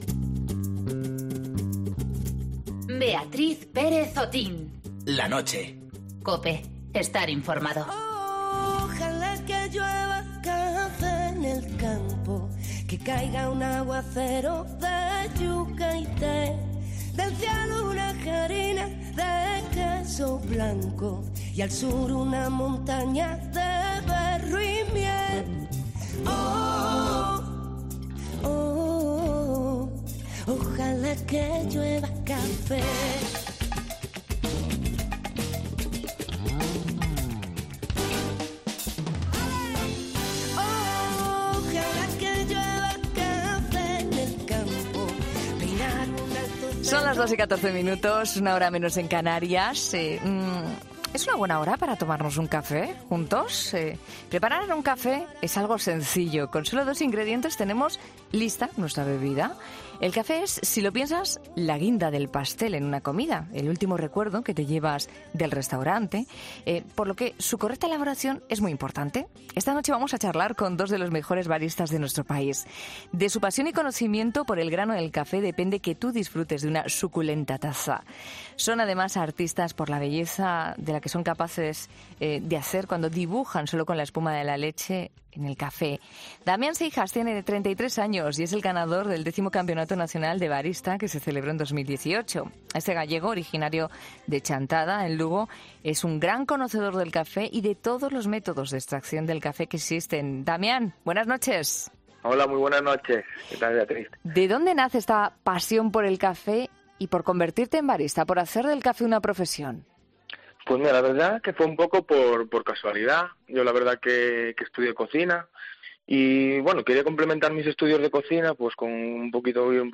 En 'La Noche' de COPE hablamos con dos de los mejores baristas de España